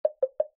Darmowe dzwonki - kategoria SMS